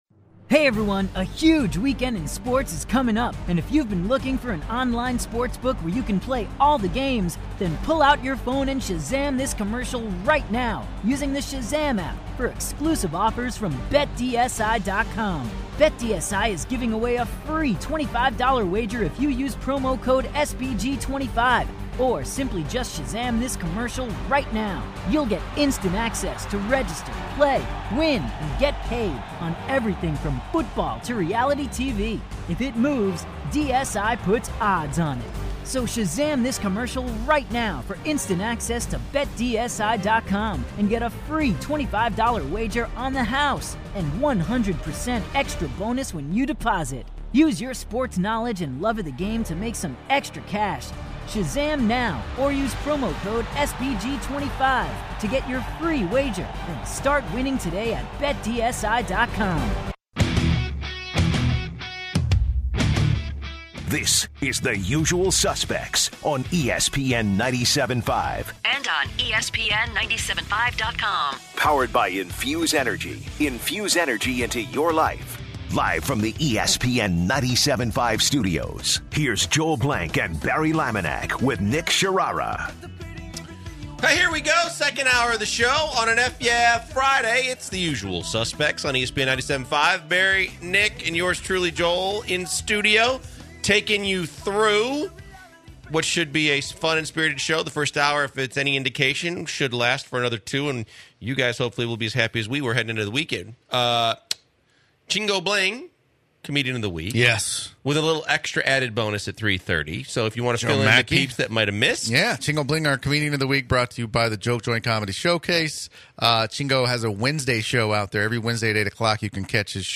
The guys keep the shop talk rolling by fielding a call from a Tesla driver to provide some insight as an electric car driver.